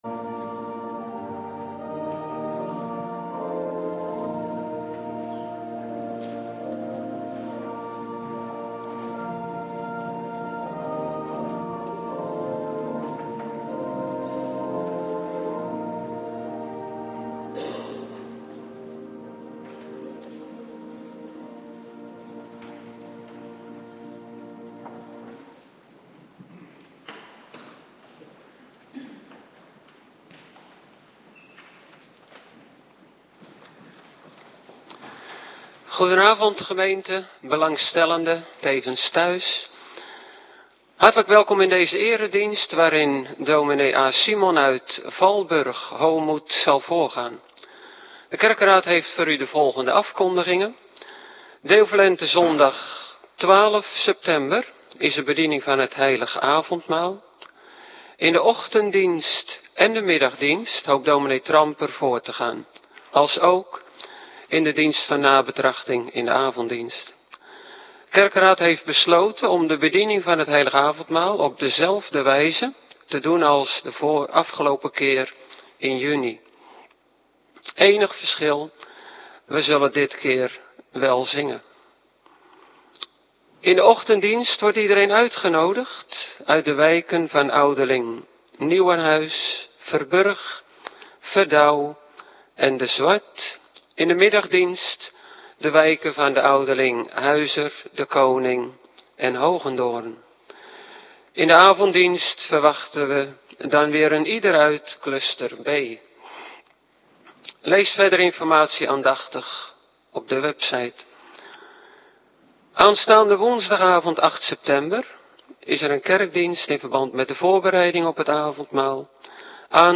Avonddienst voorbereiding Heilig Avondmaal
Locatie: Hervormde Gemeente Waarder